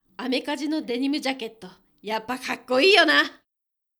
dansei_amekazinodenimujakettoyappakakkoiiyona.mp3